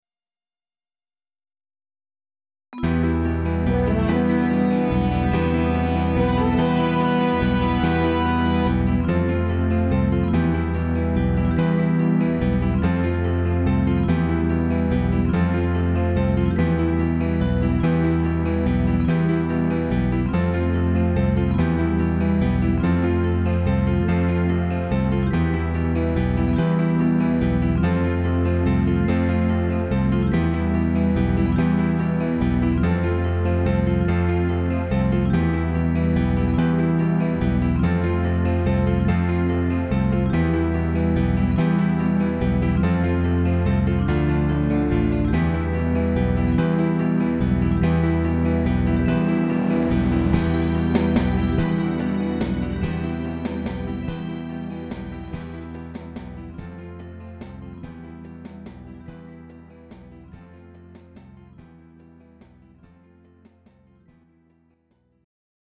Greek Ballad